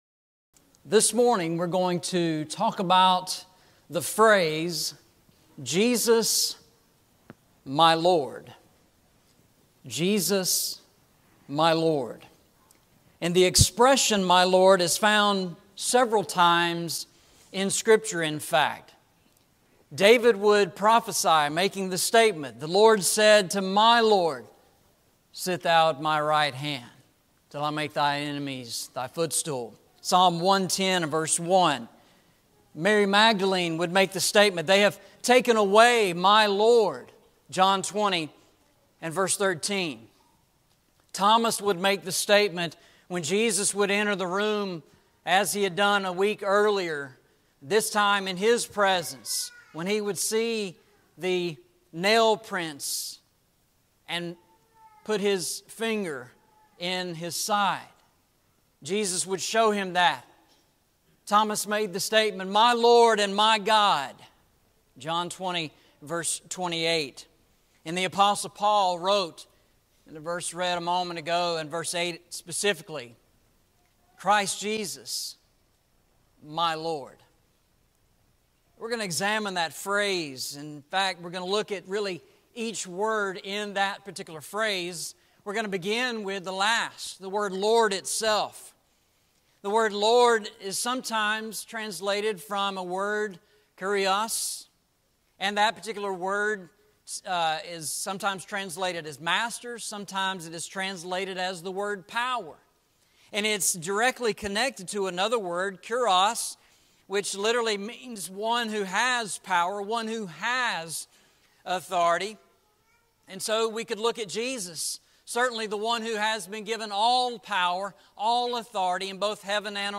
Eastside Sermons Passage: Philippians 3:8 Service Type: Sunday Morning « The New Heavens and New Earth Word Study